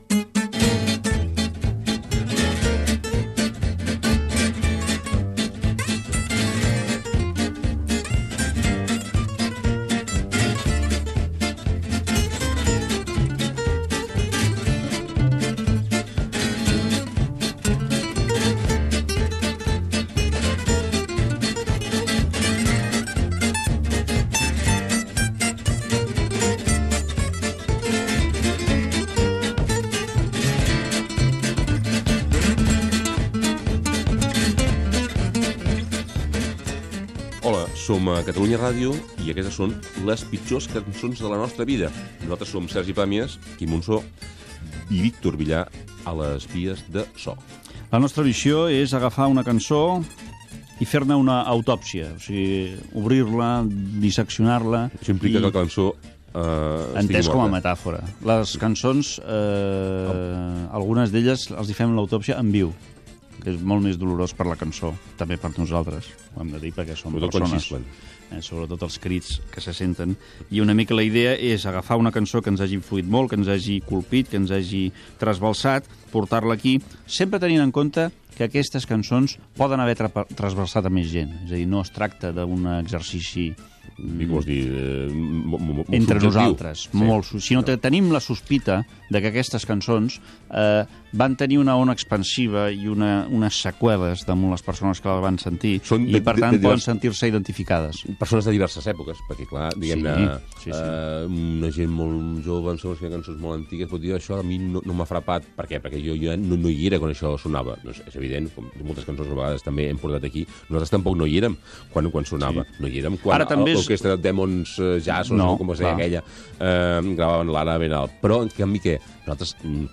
Sintonia, presentació del programa i comentaris sobre el tema musical "Maquillaje" del grup Mecano
Musical